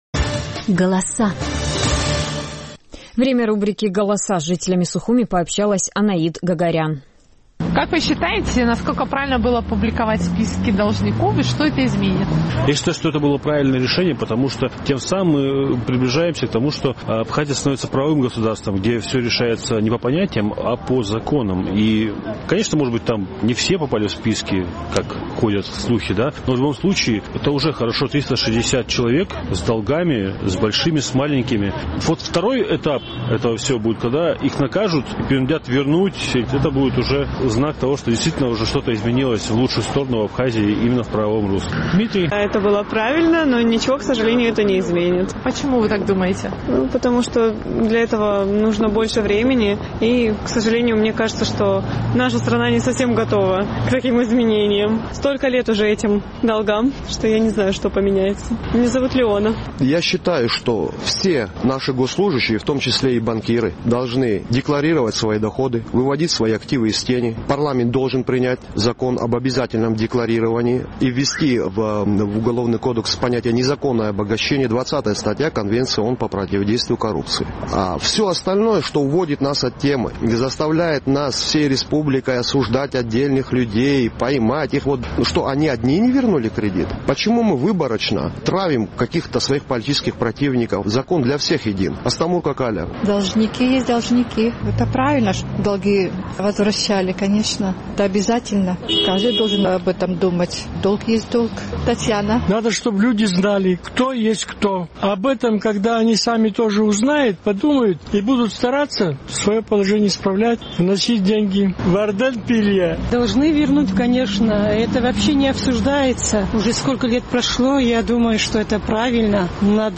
Наш сухумский корреспондент поинтересовалась мнением местных жителей, насколько правильно было публиковать списки должников и может ли это что-то изменить.